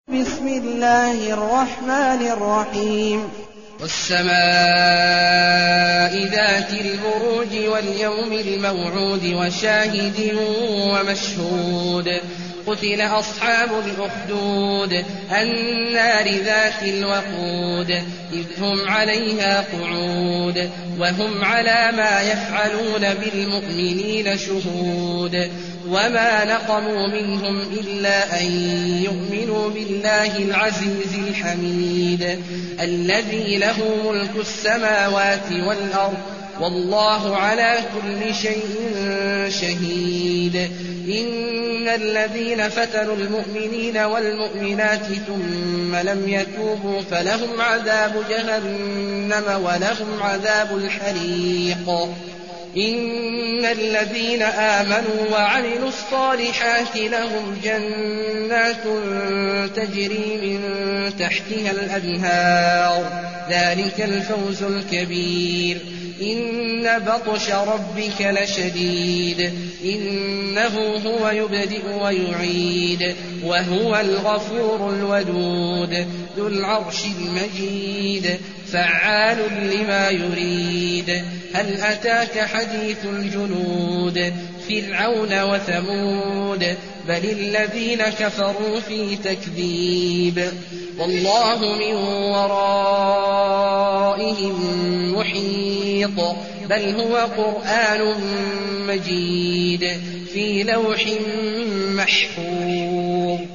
المكان: المسجد الحرام الشيخ: عبد الله عواد الجهني عبد الله عواد الجهني البروج